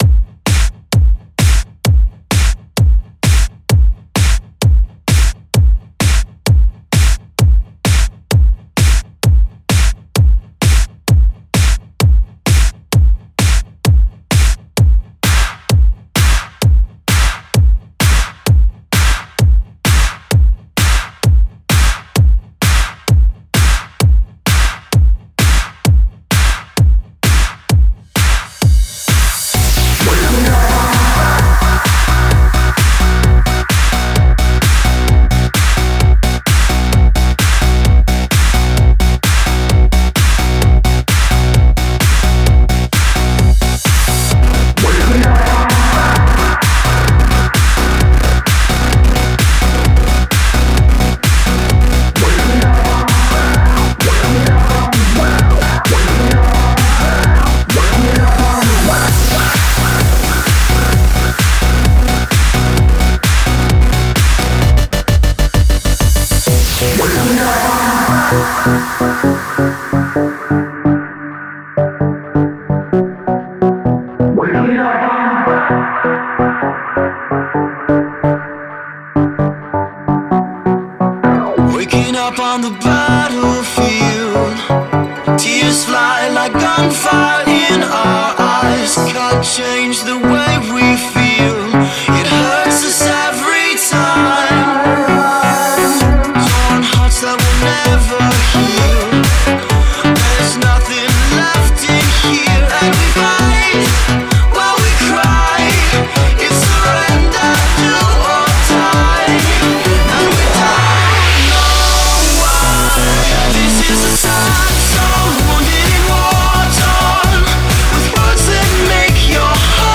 Жанр:Club